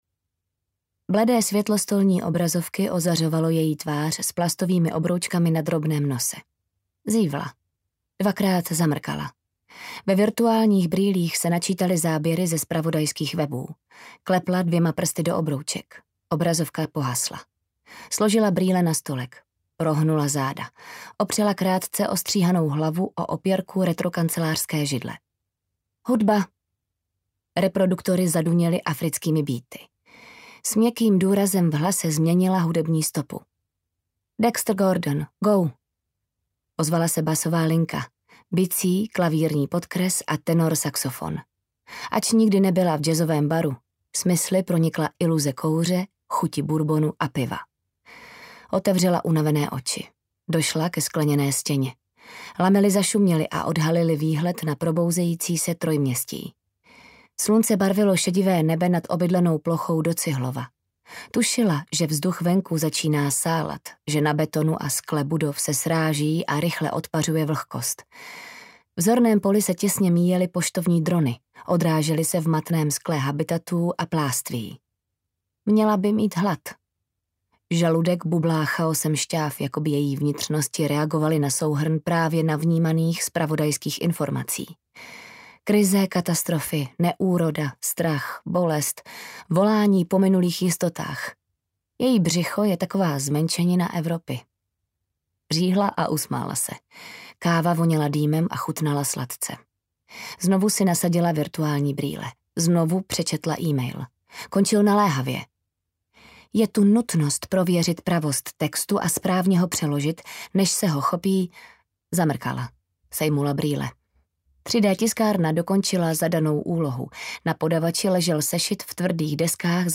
Trojměstí audiokniha
Ukázka z knihy